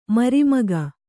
♪ mari maga